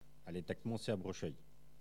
Localisation Saint-Gervais
Catégorie Locution